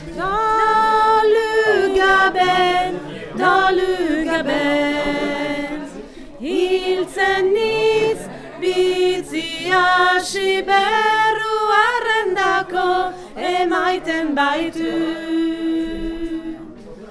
femmes_part1.mp3